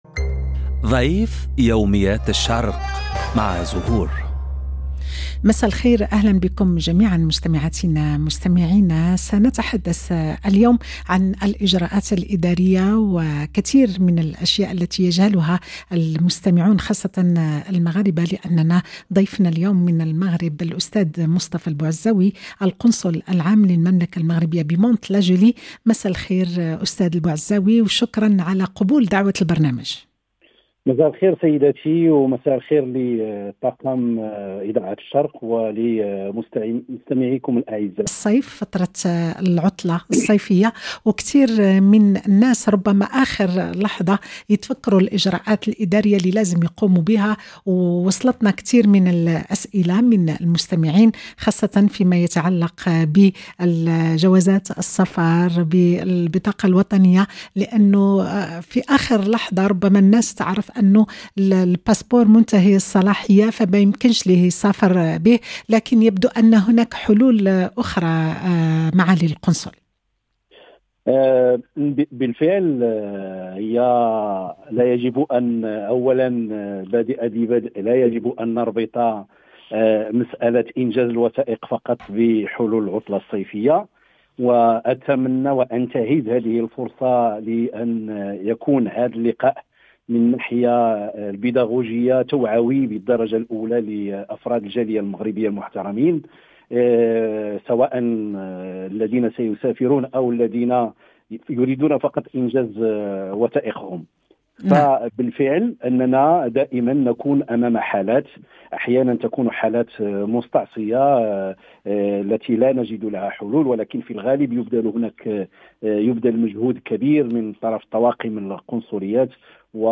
في هذا السياق، استضاف برنامج “ضيف يوميات الشرق” السيد مصطفى البوعزاوي، القنصل العام للمملكة المغربية في مدينة كانت لاجولي.